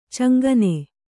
♪ caŋgane